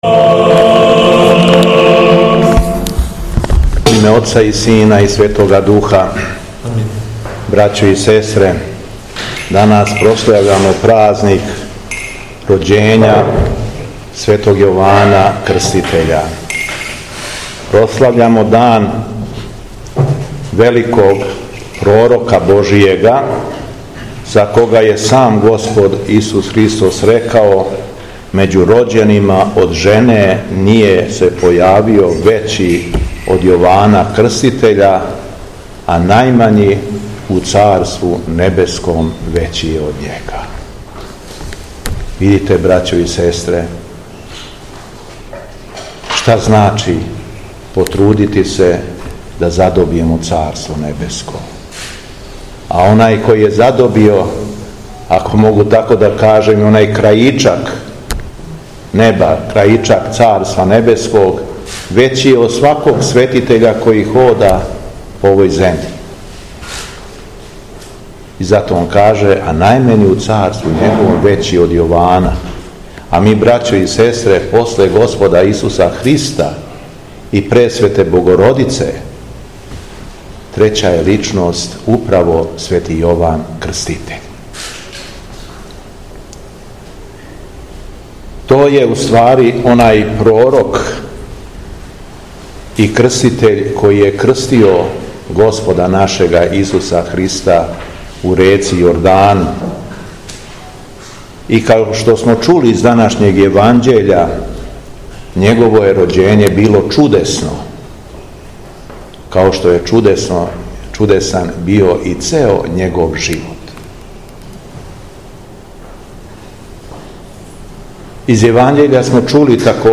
На дан прославања празника Рођења Светог Јована Крститеља, 7. јула 2025. године, Његово Високопреосвештенство Архиепископ крагујевачки и Митрополит шумадијски Господин Јован, служио је Свету Архијерејску литургију у манастиру Тресије.
Беседа Његовог Високопреосвештенства Митрополита шумадијског г. Јована
Након прочитаног Јеванђелског зачала, Митрополит се обратио беседом окупљеним верницима рекавши: